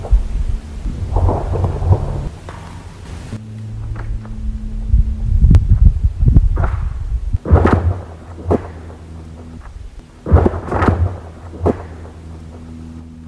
Das Wasser unter der Schicht aus Eis und Schnee gibt unheimliche Gurgelgeräusche von sich.
Der See beschwert sich: So hört es sich an